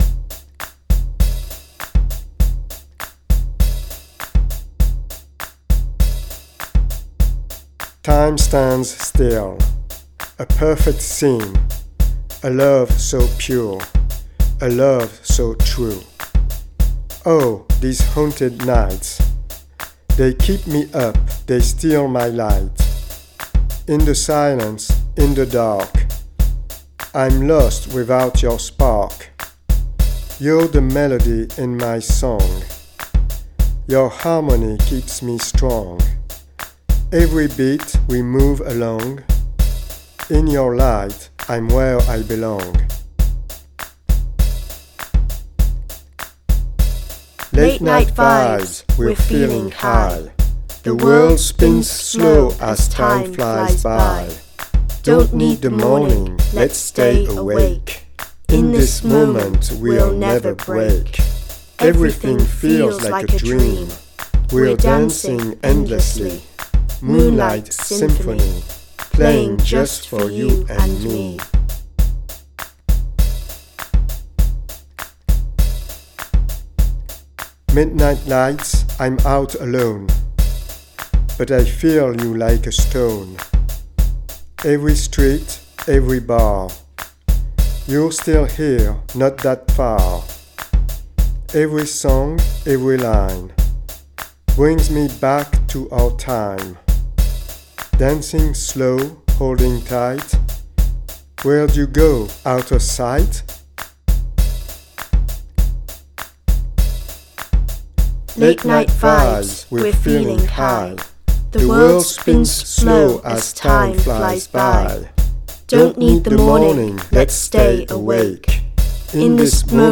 drum machine
Chorus vocals on tracks 1